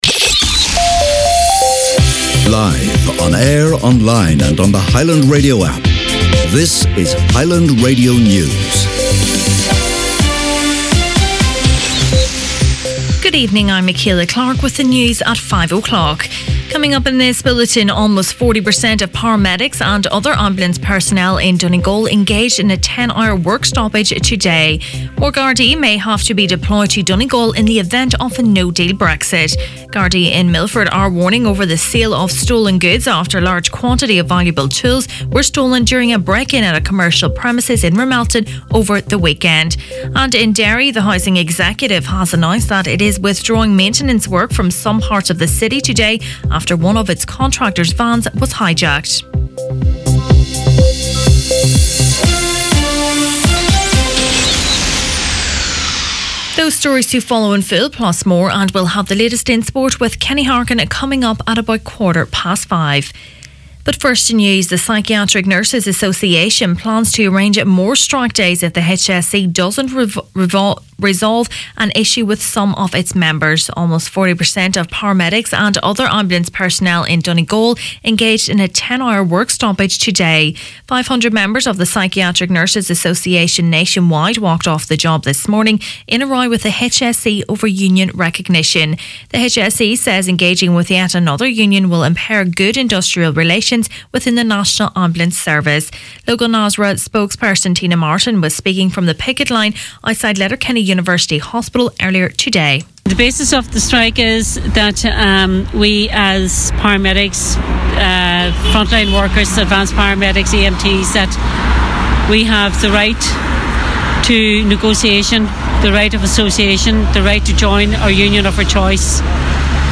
Main Evening News, Sport and Obituaries Tuesday January 22nd